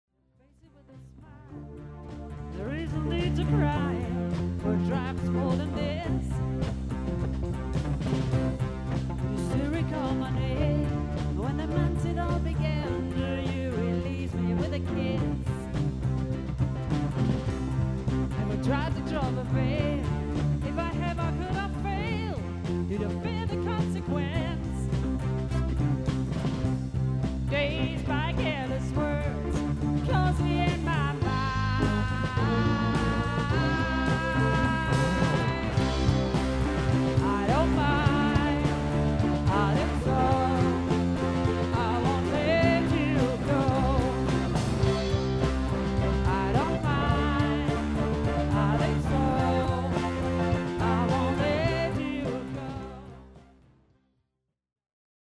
Auf dieser Seite findet Ihr Hörproben unserer Auftritte.